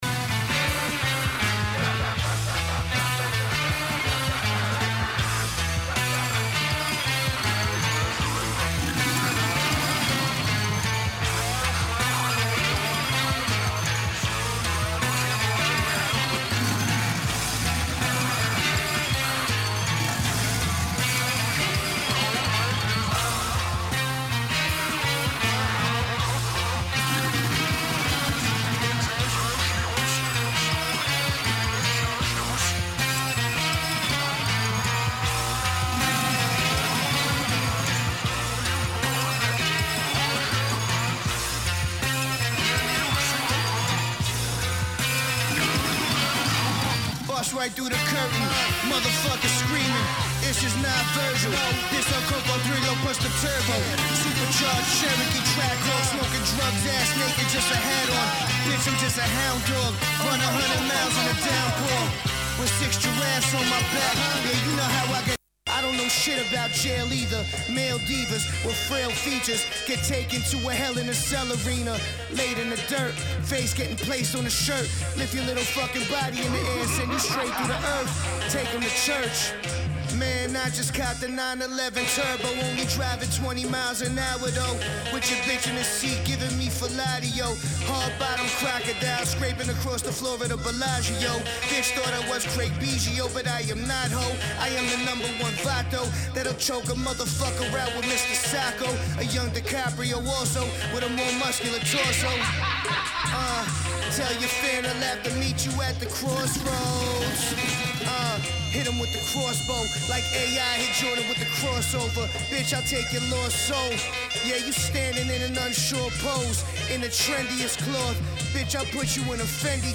⌂ > Vinyly > Hiphop-Breakbeat >